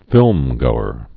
(fĭlmgōər)